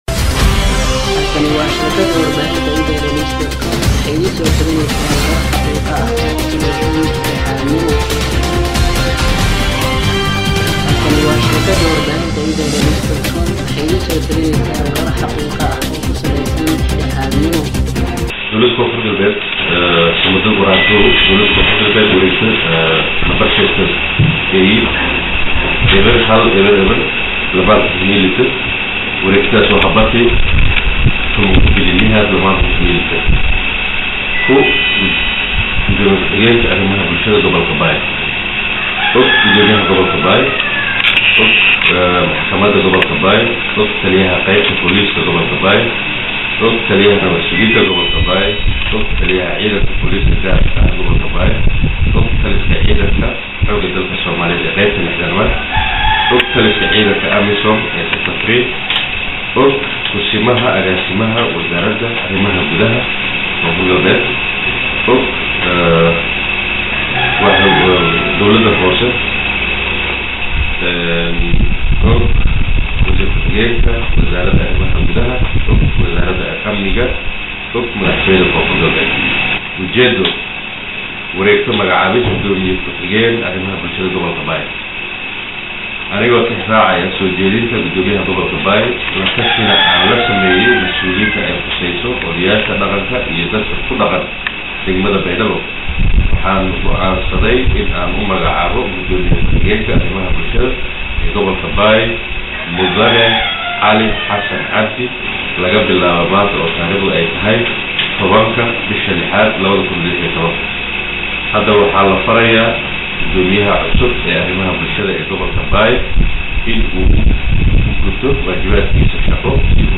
Halkan Ka Dhageyso Wareegtada oo Akhrinayo Gudoomiyaha Gobolka Bay